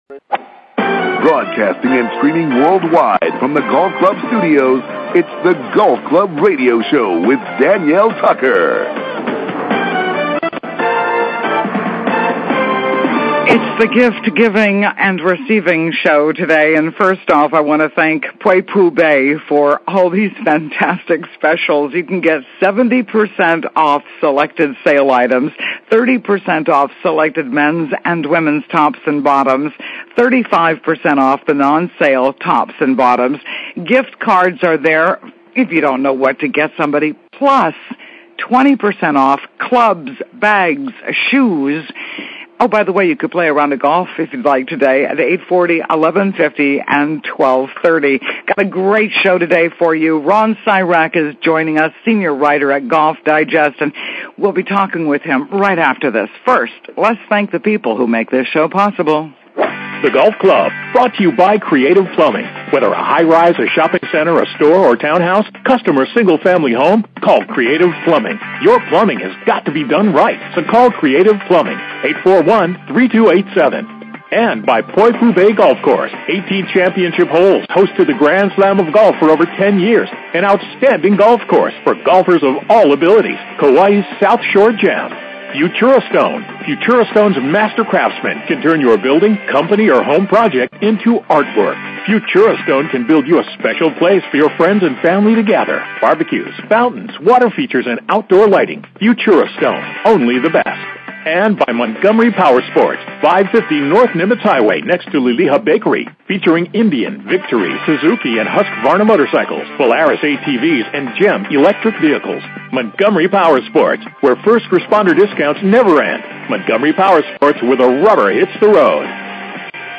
SATURDAY MORNINGS: 7:00 AM - 8:30 AM HST MAUI OAHU KAUAI HILO KONI FM 104.7 KGU FM 99.5 KTOH FM 99.9 KPUA AM 670 The Golf Club Radio Show Click here to listen using a MP3 file.